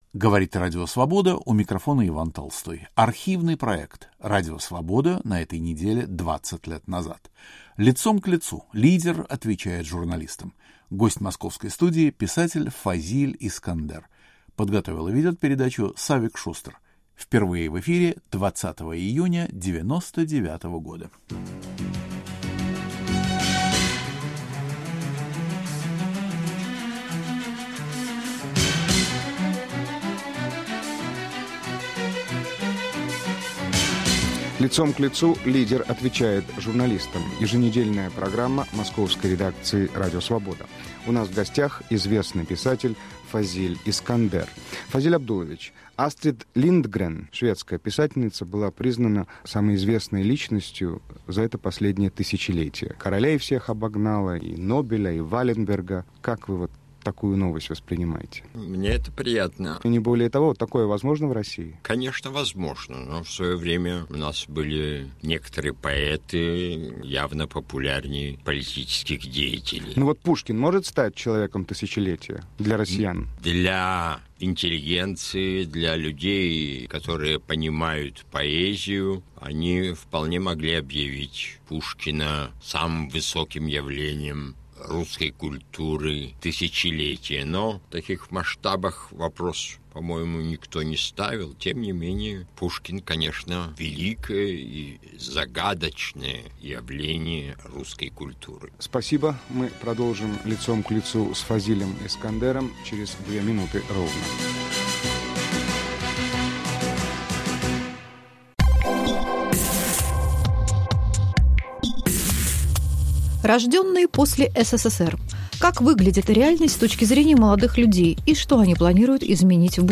Писатель Фазиль Искандер в студии Радио Свобода отвечает на вопросы журналистов - россиян и итальянца: может ли литератор в современной России быть избранным человеком тысячелетия, о прошлом и будущем страны, о военной операции НАТО в Югославии и грузино-абхазской войне, об идеальном государственном правителе и многом другом. Автор и ведущий Савик Шустер.